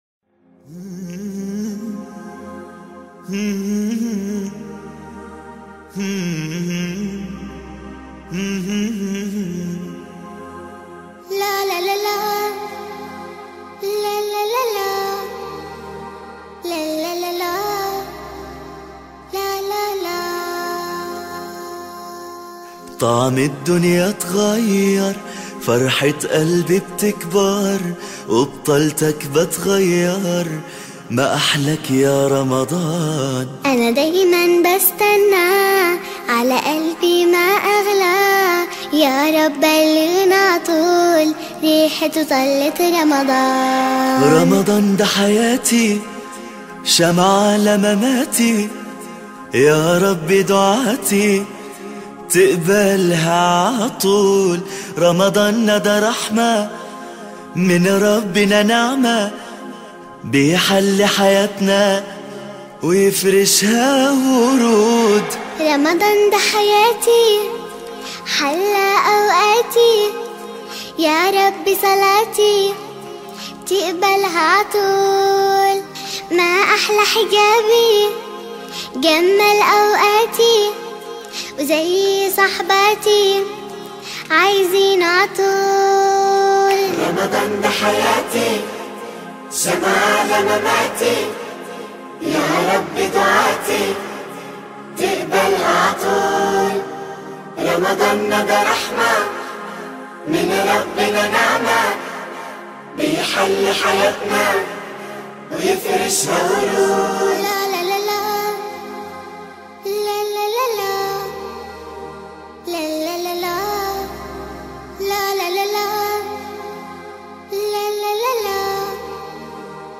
It’s an Arabic Nasheed in two sweet voices